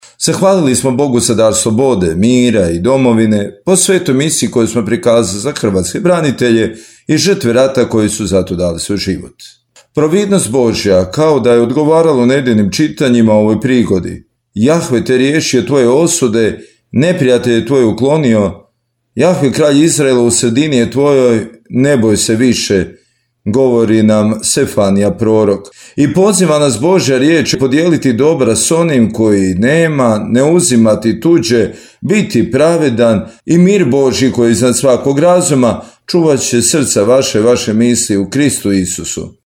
Đulovac – molitva za mir